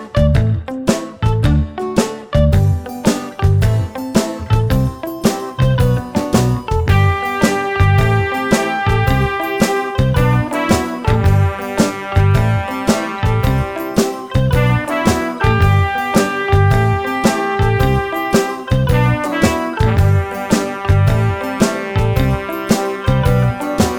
no Backing Vocals Soundtracks 2:22 Buy £1.50